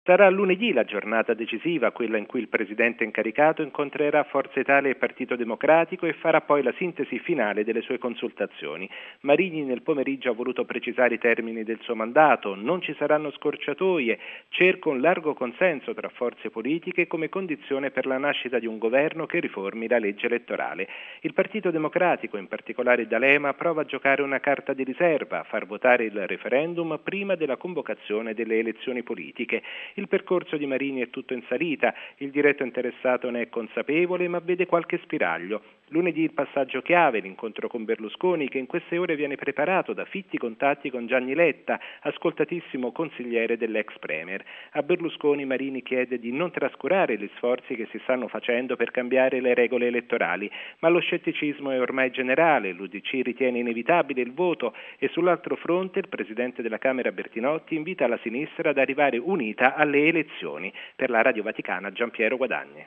Marini vede un piccolo spiraglio e chiede la collaborazione di Forza Italia. Servizio